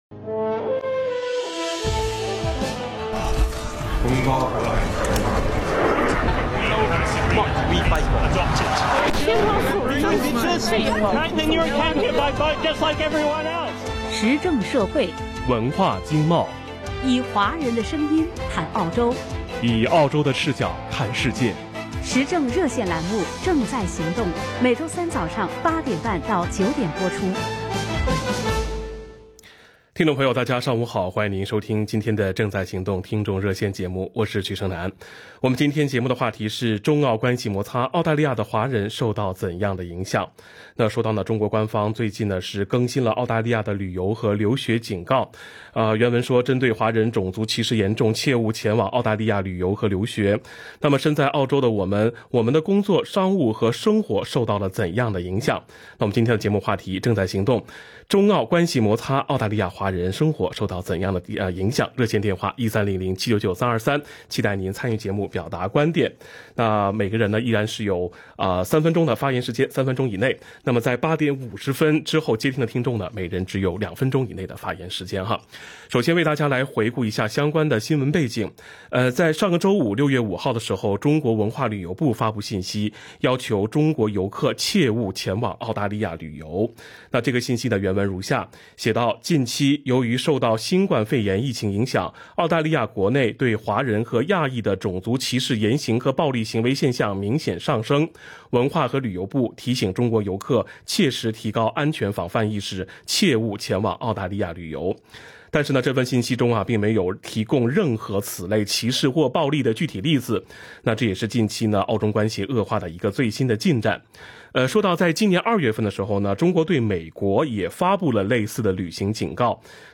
action_talkback_june_10._newmp3.mp3